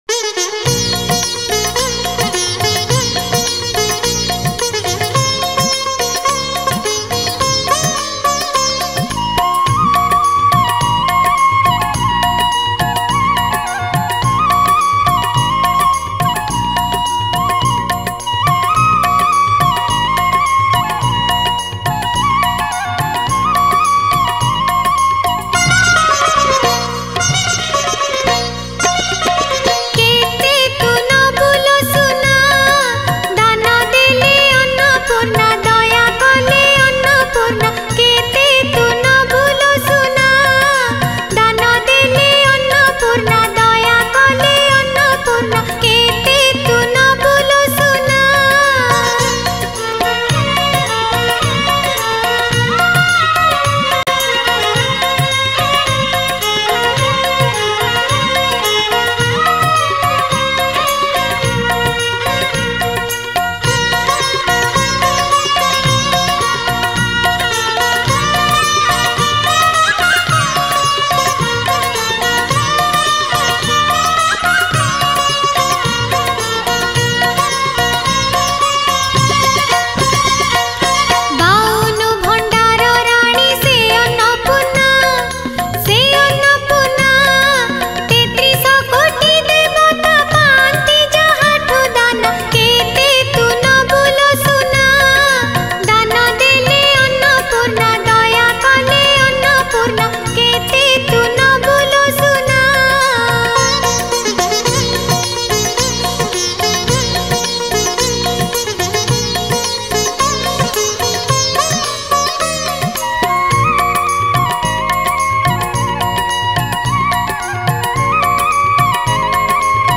Category : Manabasa Gurubara Bhajan